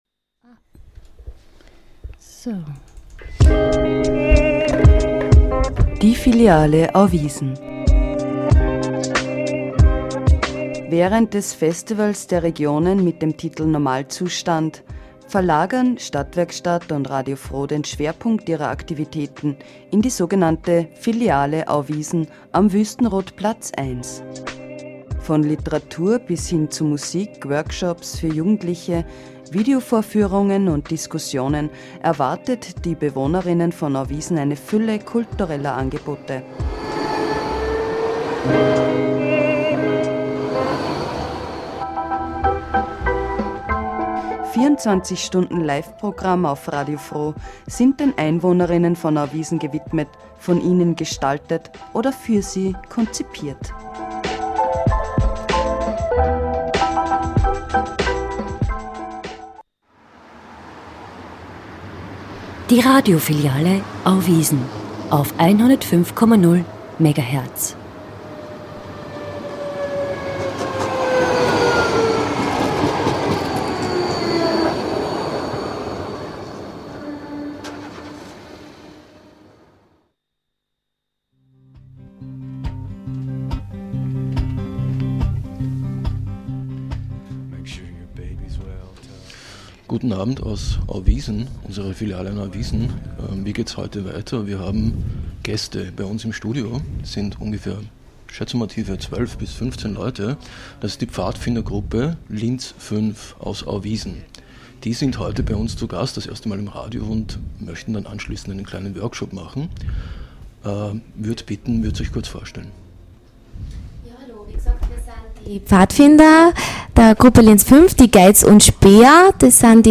Die Pfadfindergruppe Linz 5 aus Auwiesen zu Gast im Studio der Filiale Auwiesen. Sie erzählen, wie man Pfadfinder wird und was es eigentlich mit den Pfadfinder-Halstüchern auf sich hat.